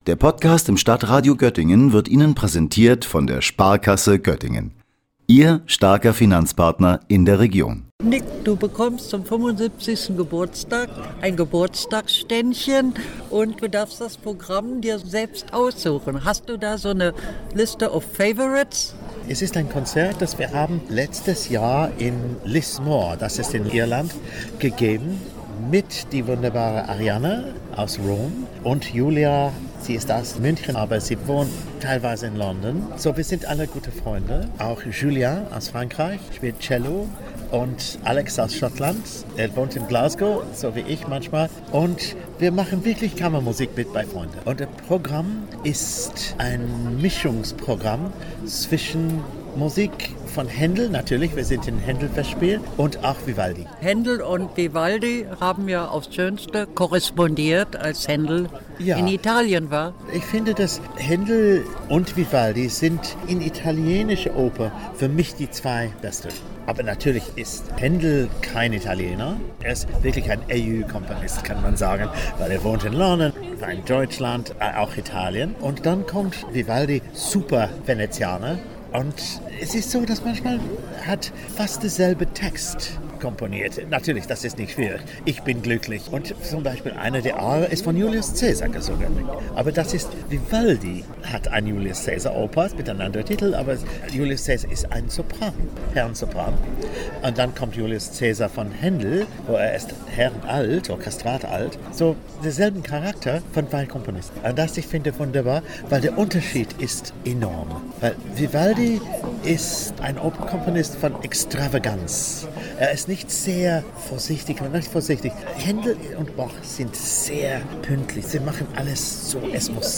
– Gespräch mit Nicholas McGegan über sein Geburtstagsständchen anlässlich der Internationalen Händel Festspiele - StadtRadio Göttingen